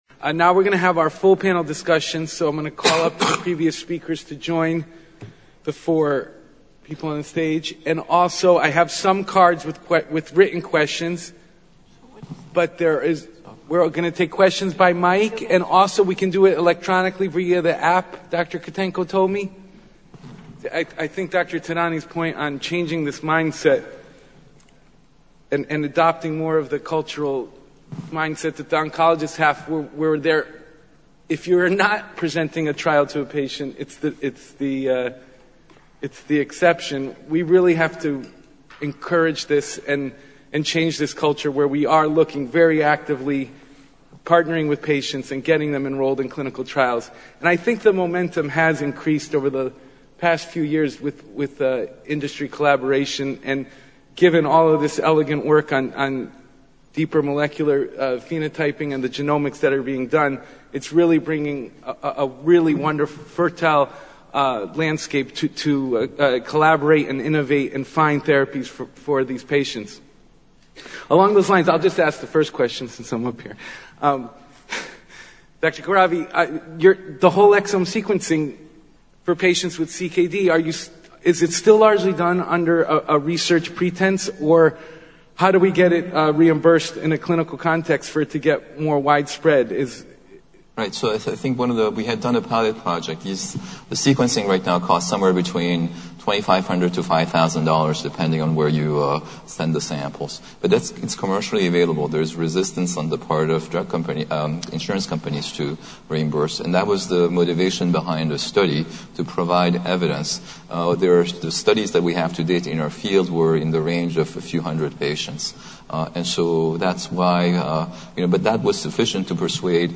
Panel Discussions